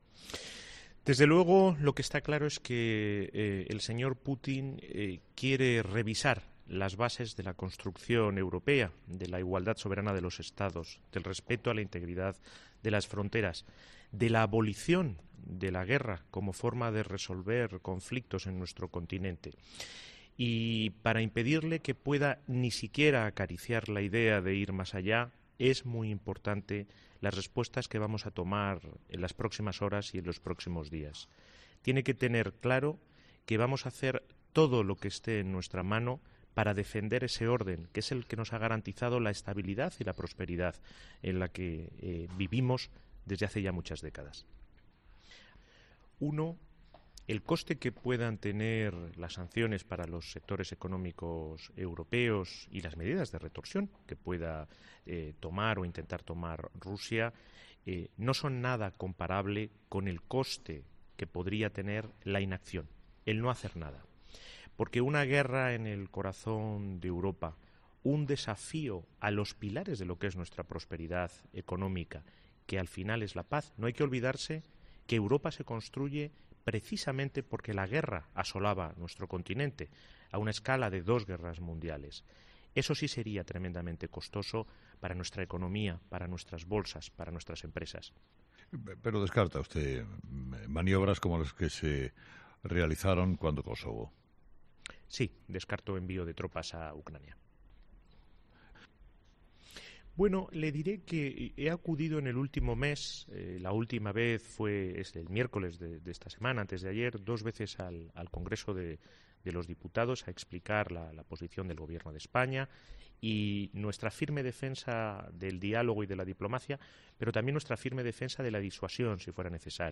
La entrevista al ministro José Manuel Albares en COPE, en cinco frases